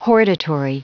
Prononciation du mot hortatory en anglais (fichier audio)
Prononciation du mot : hortatory